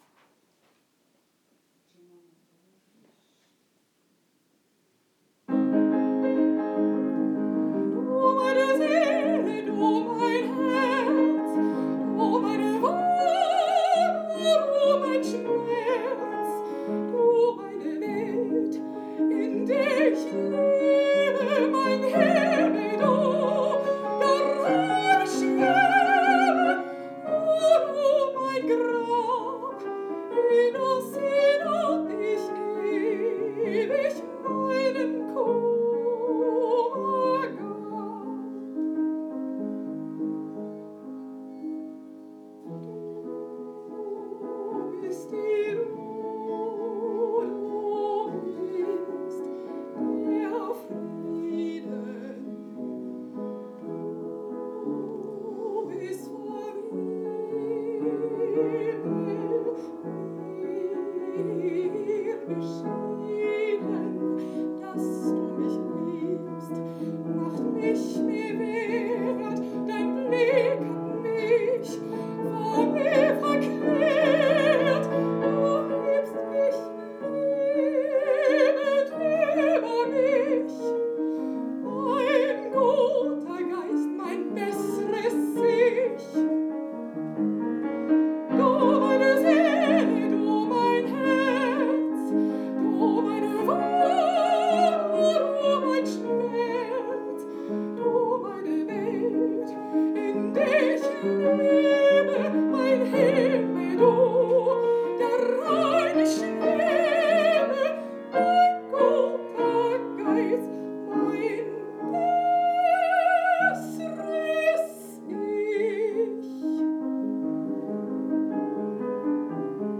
von Robert Schumann | (Live- Mitschnitt)